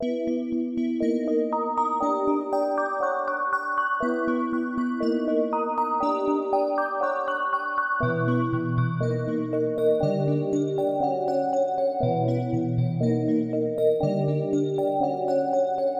万圣节钢琴
Tag: 120 bpm Hip Hop Loops Piano Loops 2.69 MB wav Key : Unknown